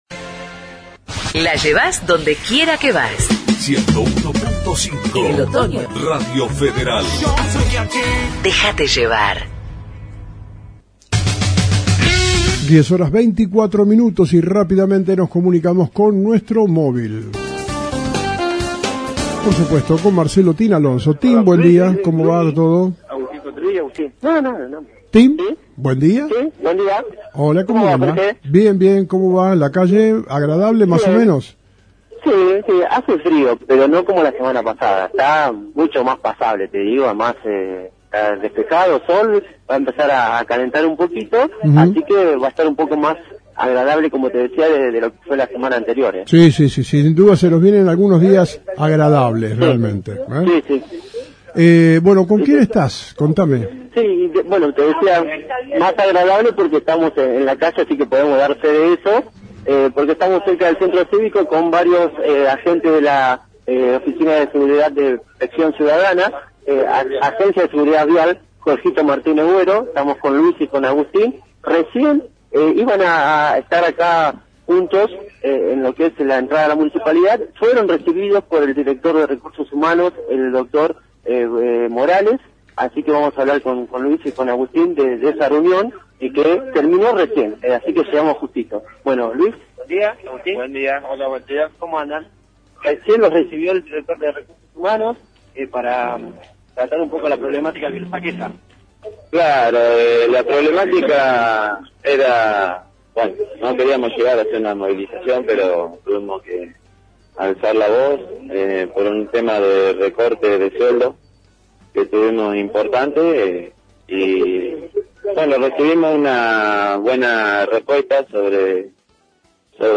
Entrevista con Empleados del sector